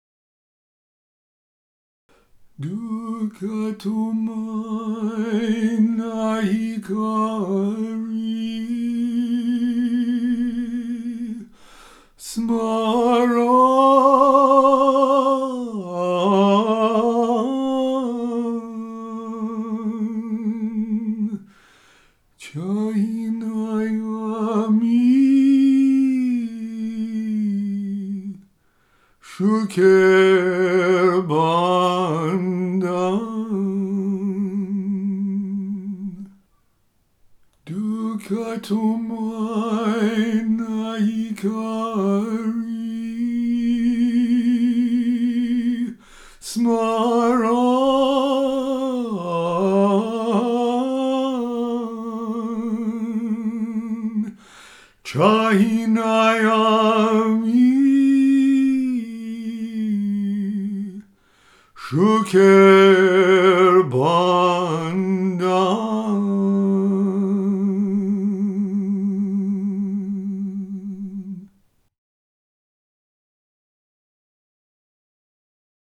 Music for meditation and relaxation.